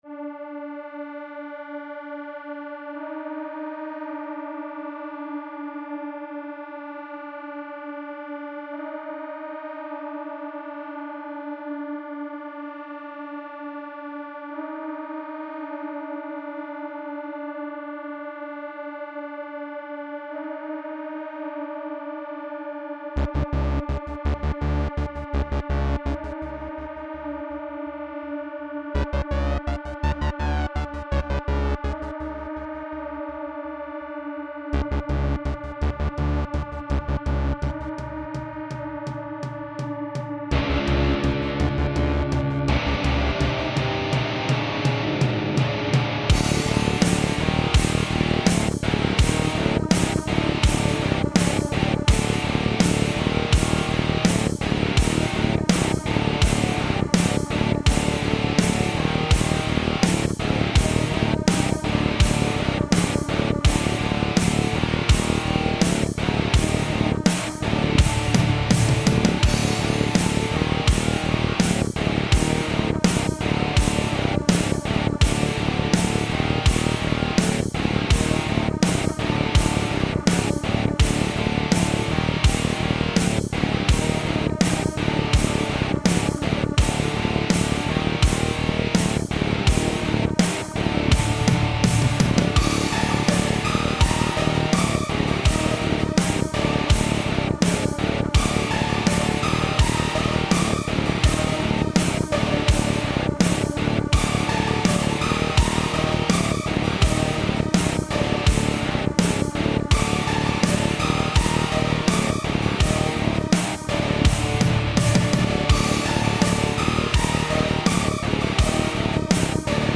Cyber/Doom/Sludge metal
Ударные из сэмларей качаных. Бас, пэды, лиды, гейты - вангуард.